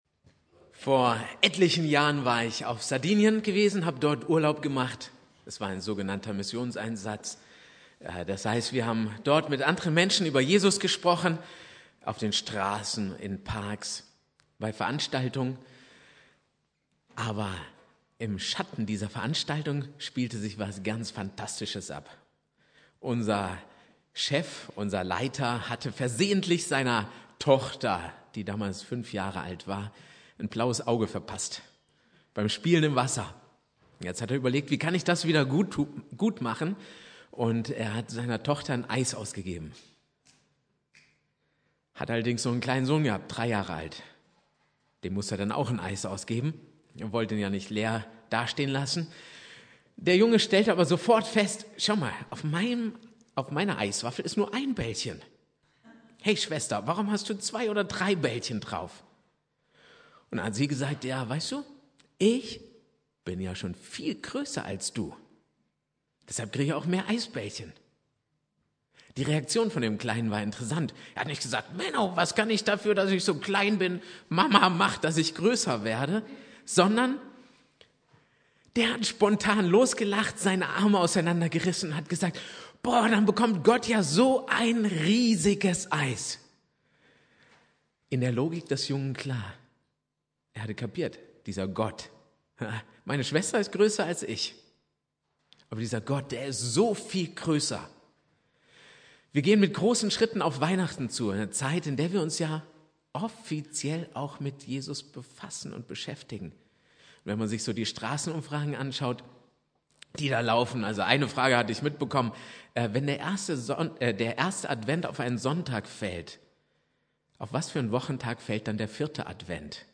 Predigt
2.Advent Prediger